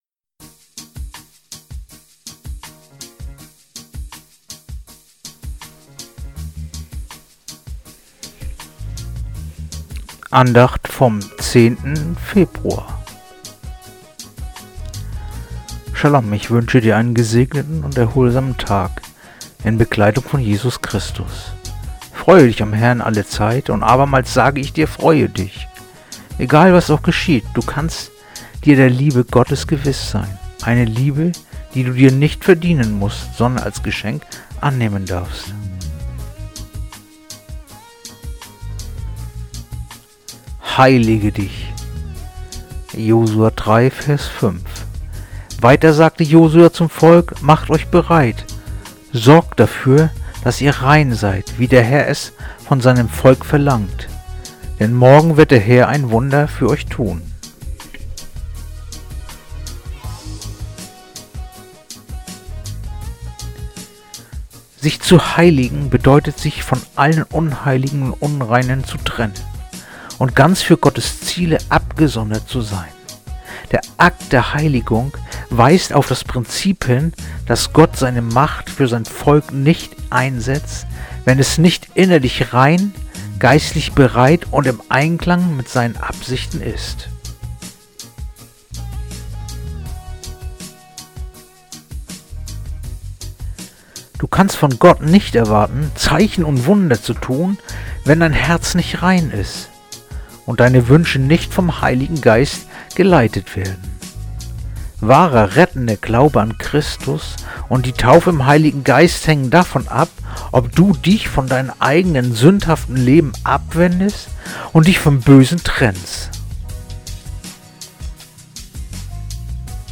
Andacht-vom-10.-Februar-Josua-3-5.mp3